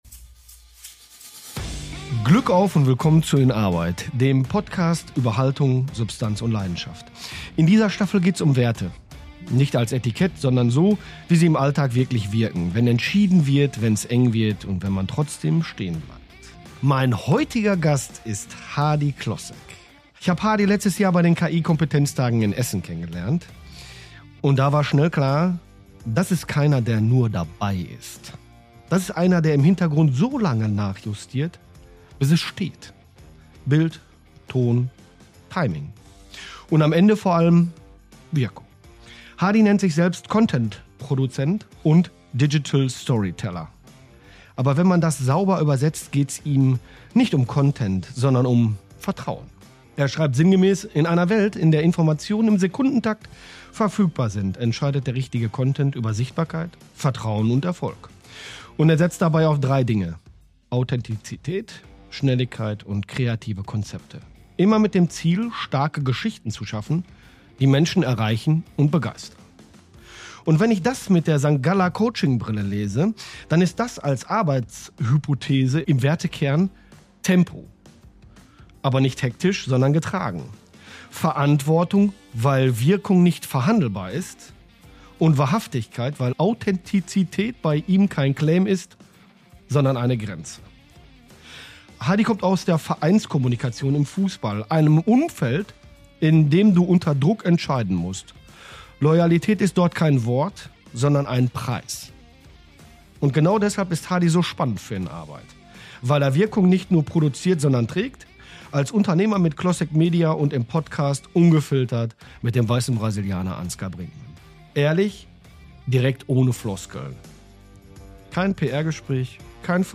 Ein Gespräch über Schnelligkeit, die wichtiger ist als Schönheit, über Freundlichkeit als nicht verhandelbaren Kompass und über die Frage, warum der Fußball seinen Zauber verliert, sobald man hinter die Kulissen schaut.